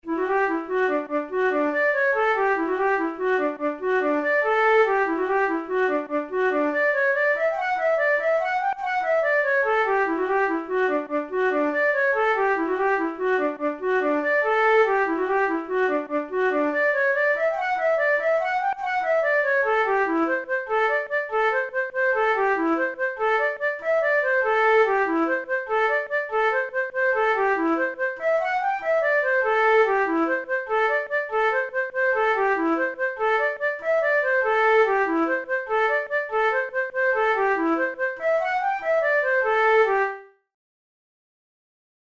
InstrumentationFlute solo
KeyD major
Time signature6/8
Tempo96 BPM
Jigs, Traditional/Folk
Traditional Irish jig